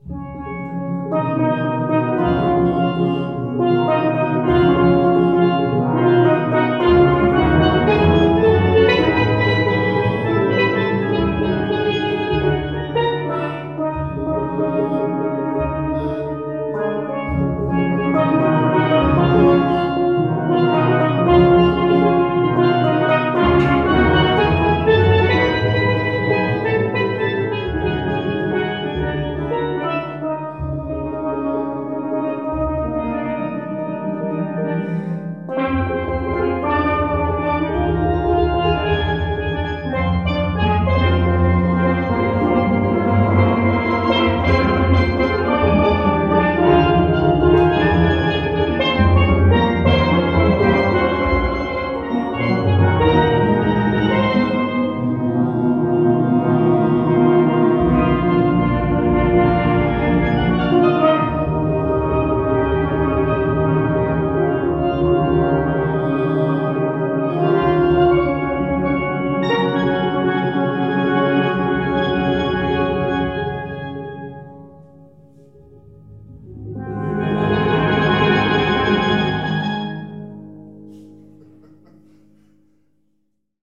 Ever heard a Steel Pan Orchestra? Here's one rehearsing in a place called The Tabernacle, back in November of 2017. My dad is teaching a group of players part of a track he wants them to learn, and this was captured using some Cad M179 microhpones tattached to a Jecklin disk, paird with an OlympusLS-100 recorder.